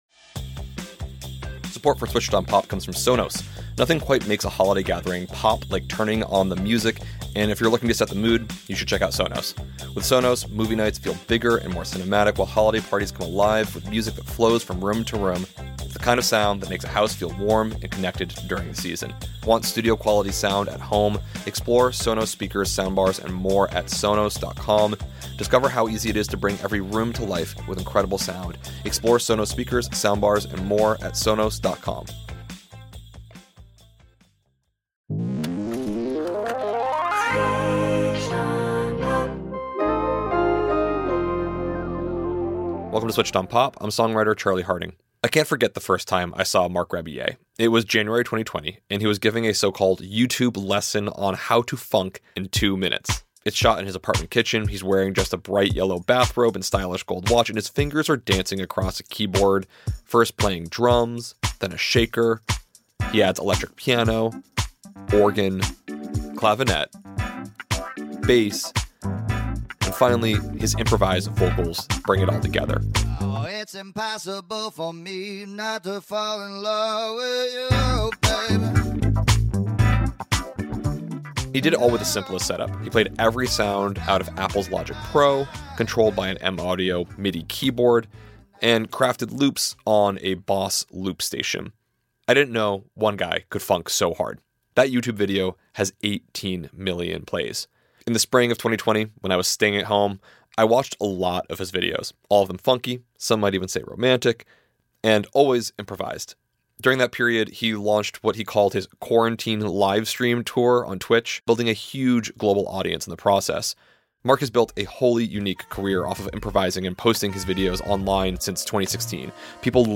witnessing his flow state firsthand as he graces us with some live improvisation.